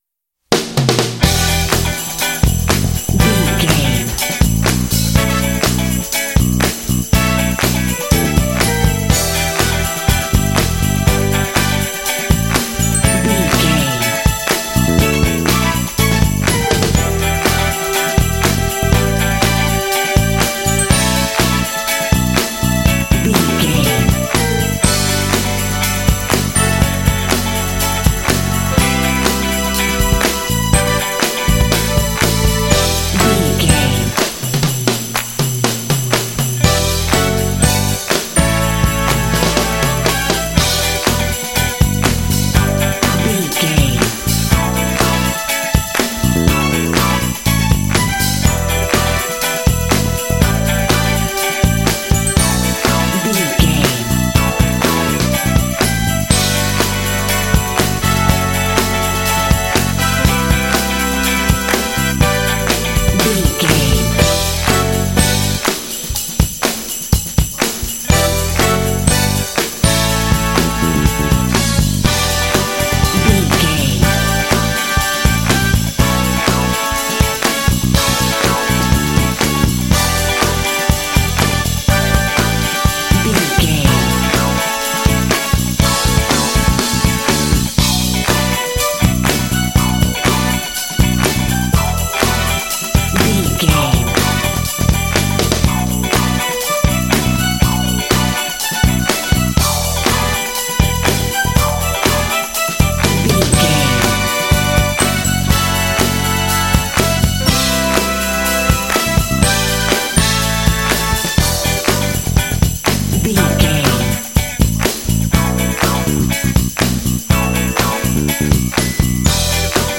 Aeolian/Minor
funky
groovy
driving
energetic
lively
festive
strings
bass guitar
electric guitar
drums
brass
percussion